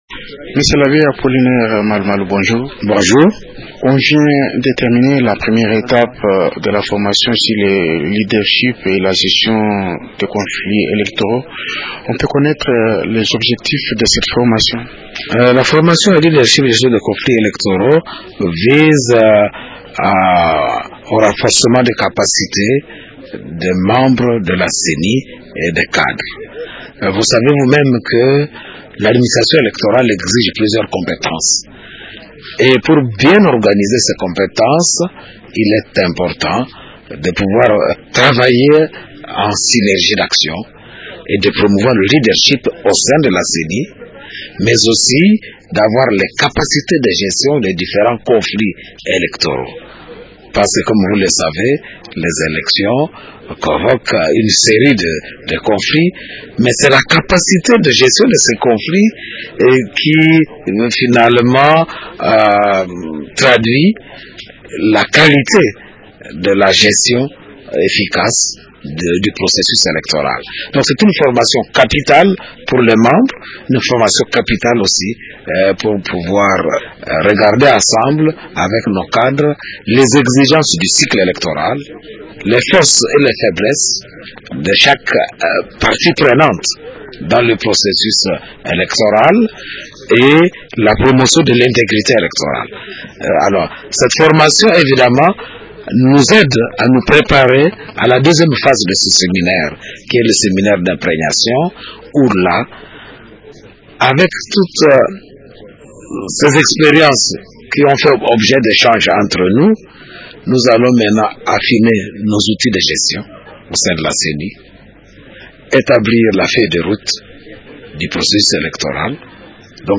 L’Abbé Apollinaire Malu-Malu, président de la Ceni, s’entretient